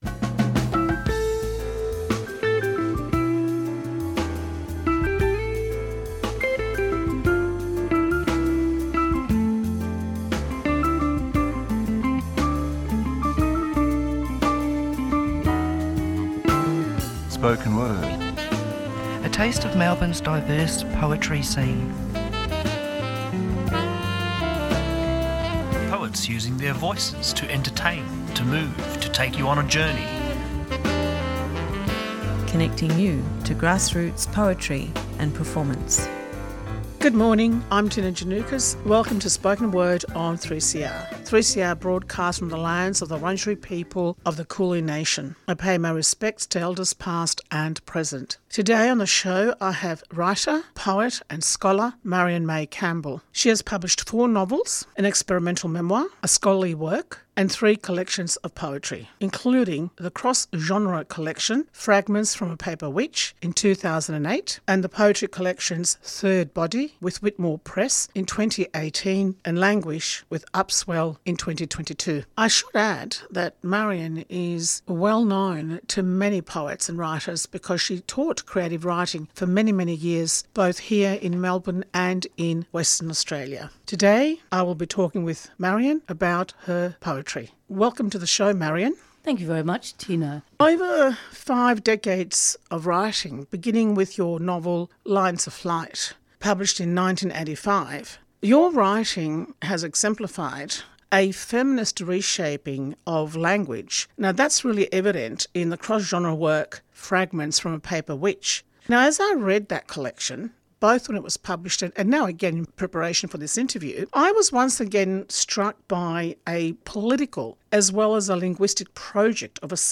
Tweet Spoken Word Thursday 9:00am to 9:30am A program dedicated to the eclectic world of poetry and performance.